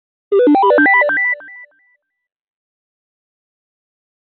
Soundeffekte